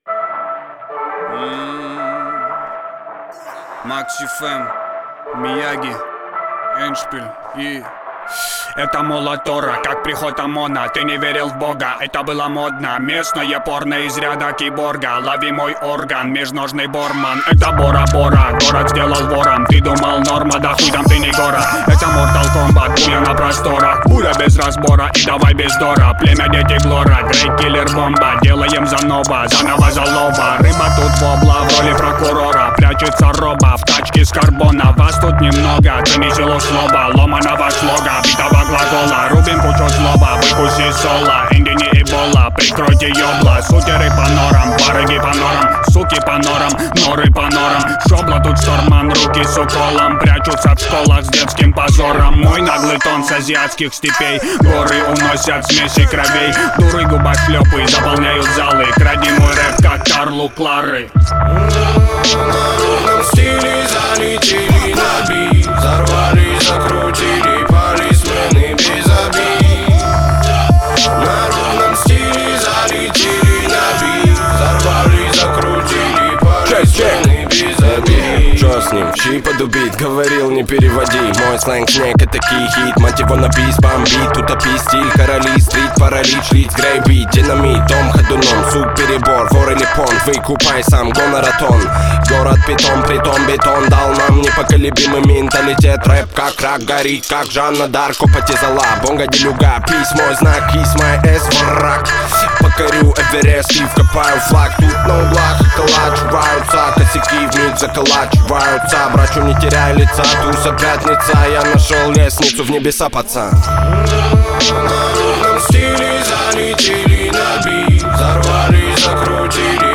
это трек в жанре хип-хоп с элементами R&B